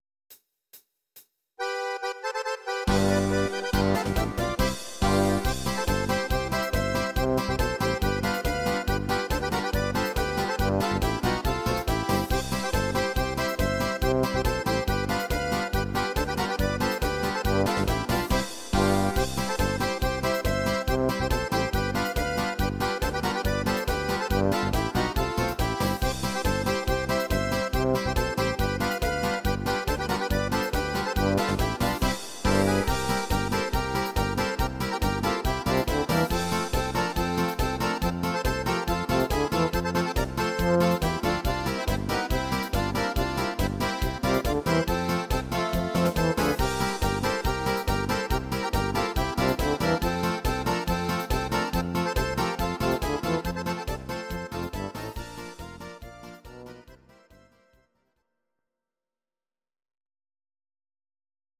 These are MP3 versions of our MIDI file catalogue.
Please note: no vocals and no karaoke included.
Akkordeon